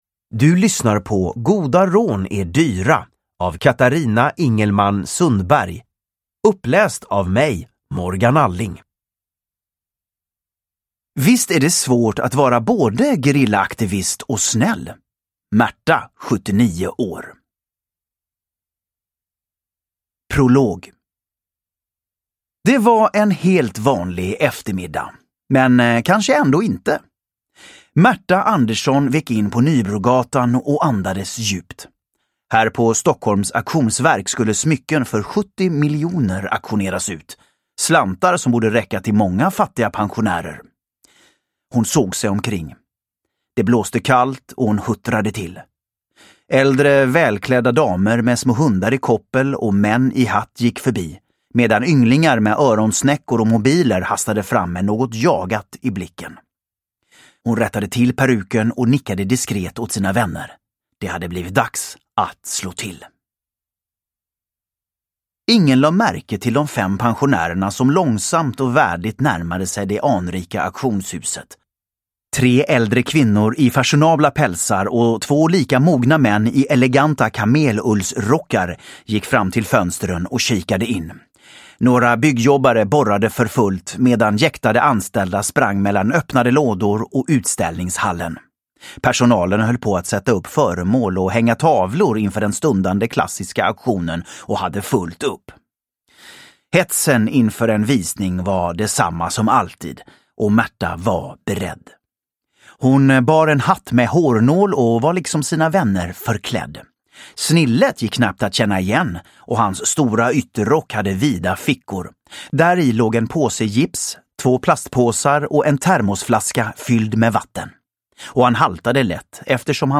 Goda rån är dyra – Ljudbok
Uppläsare: Morgan Alling